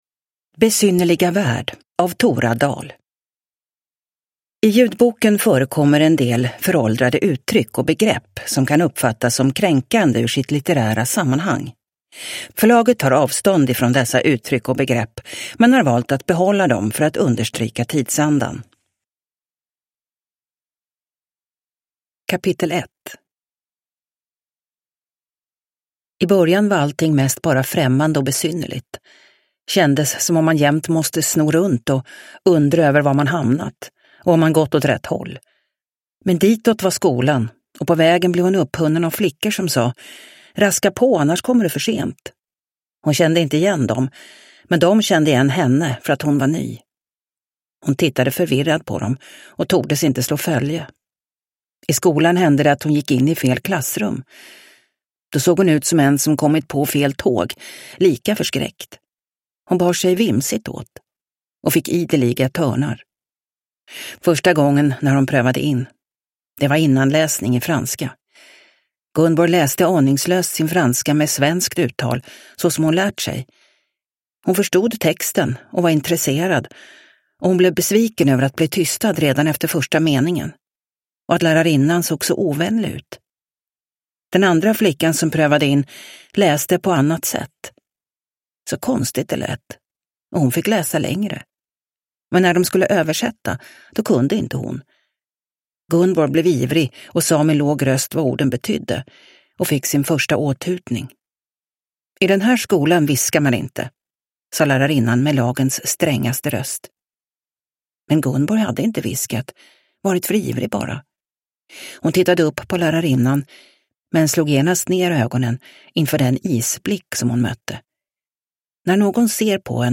Besynnerliga värld – Ljudbok – Laddas ner